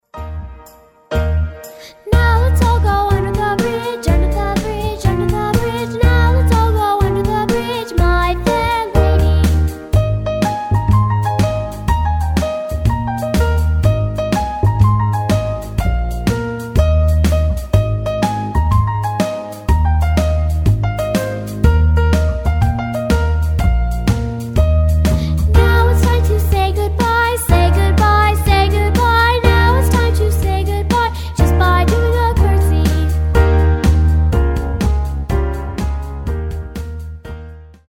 with vocal instruction